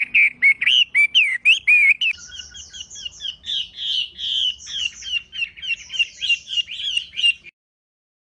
野外画眉母鸟叫声 打口提性